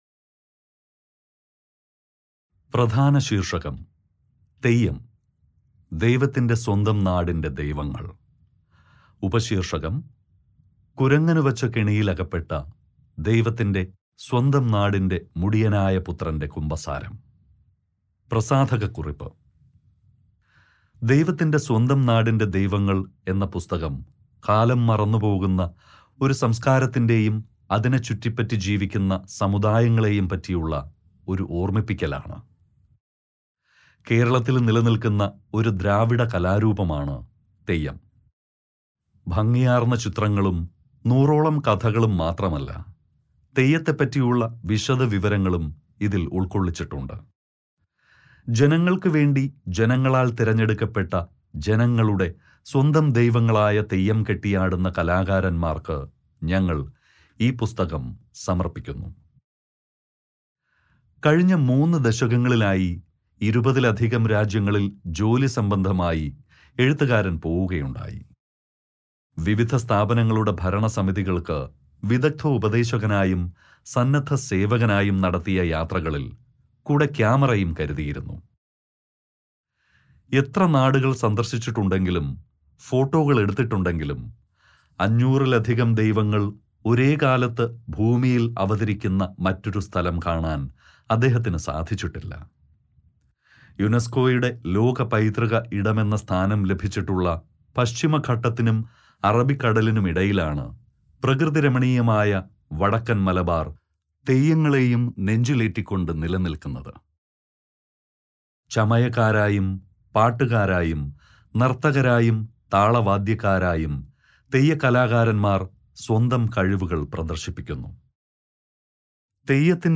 Download Audiobook Sample